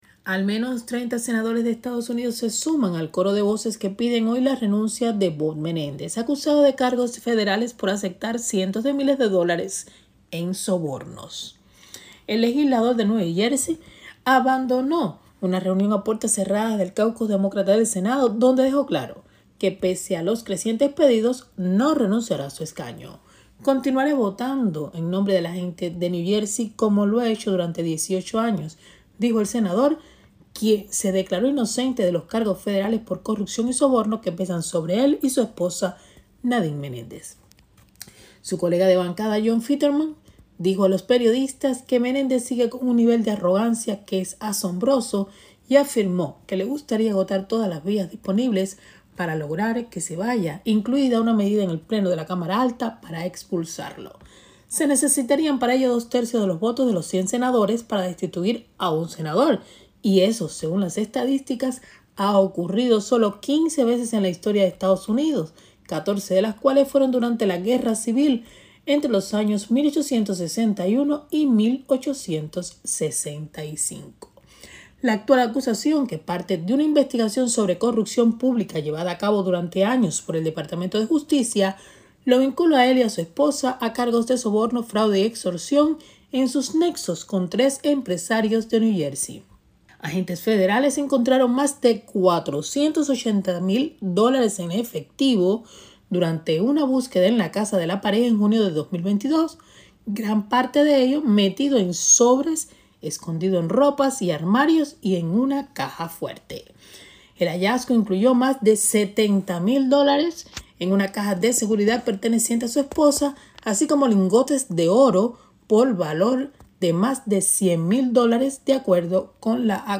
desde Washington DC